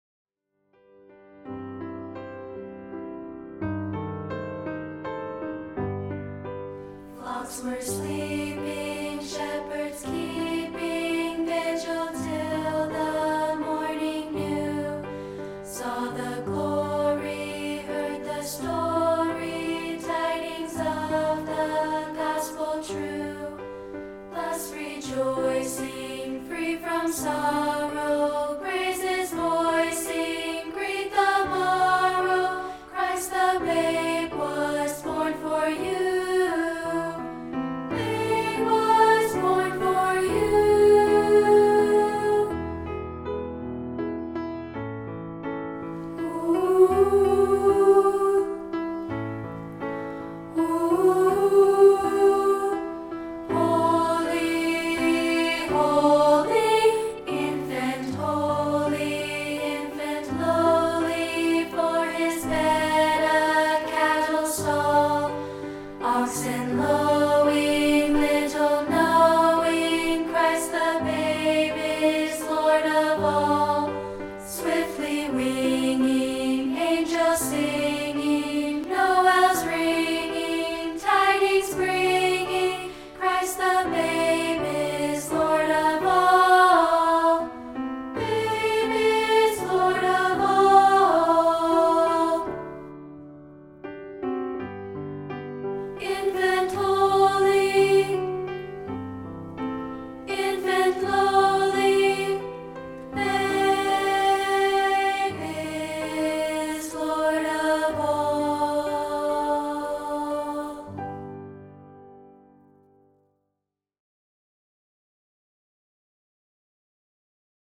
Christmas song
including this rehearsal track of part 2, isolated.